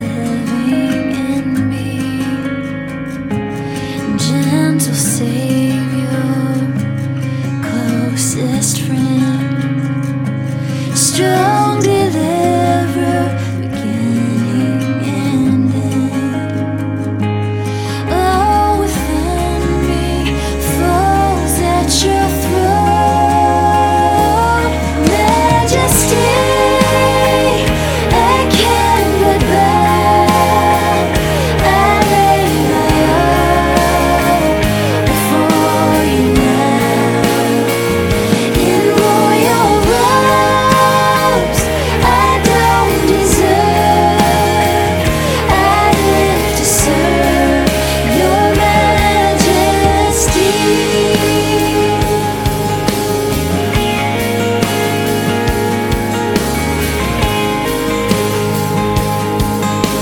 • Sachgebiet: Praise & Worship